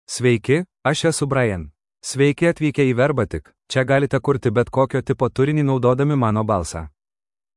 MaleLithuanian (Lithuania)
BrianMale Lithuanian AI voice
Voice sample
Male
Brian delivers clear pronunciation with authentic Lithuania Lithuanian intonation, making your content sound professionally produced.